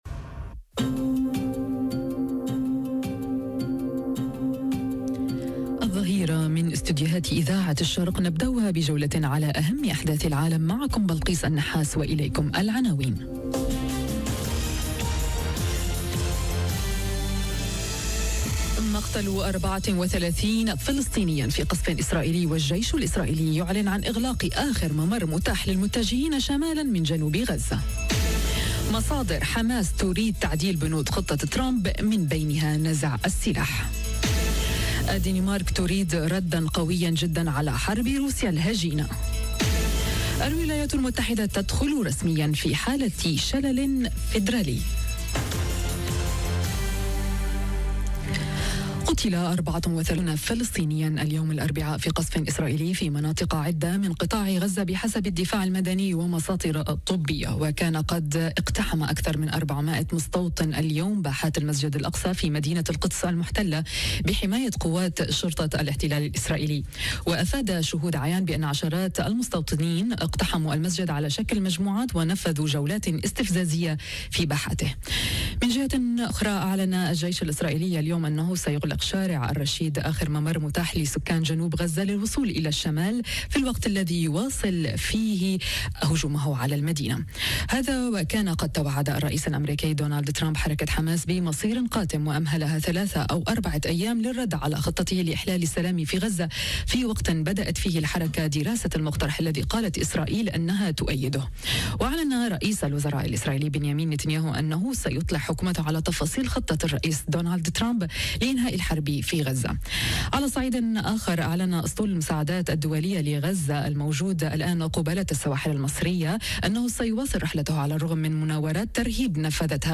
نشرة أخبار الظهيرة: ترحيب عربي ودولي بالخطة الأمريكية تقابلها انتقادات وزراء اليمين المتطرف في الحكومة الإسرائيلية - Radio ORIENT، إذاعة الشرق من باريس